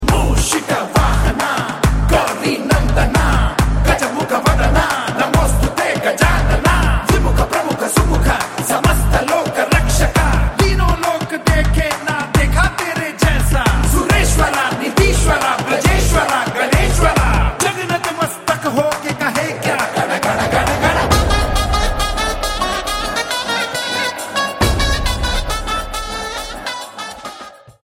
energetic